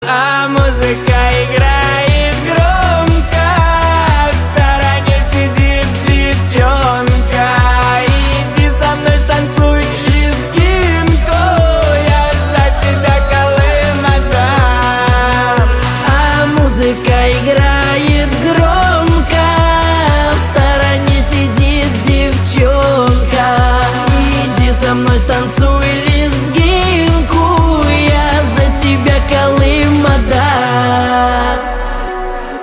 • Качество: 256, Stereo
громкие
попса